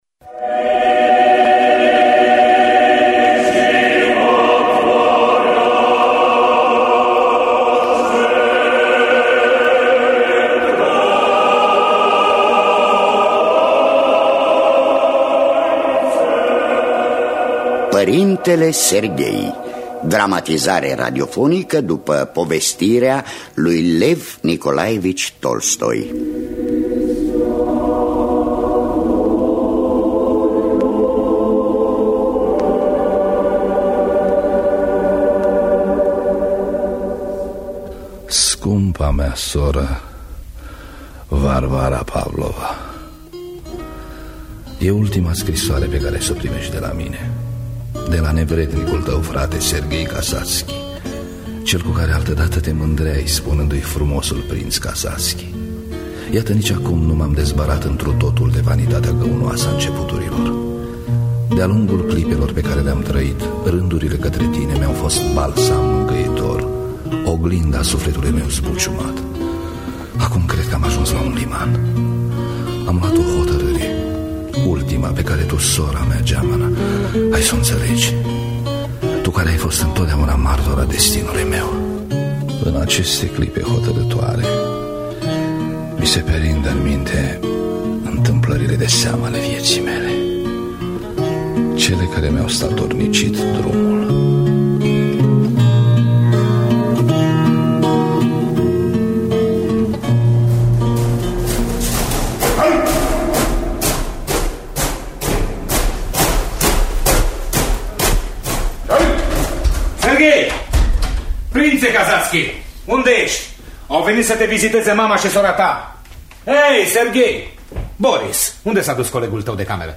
Dramatizarea radiofonică de Rodica Suciu Stroescu.